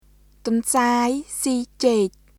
[トンサーイ・シー・チェーク　tʷɔ̀ｎsaːi siː ceːk]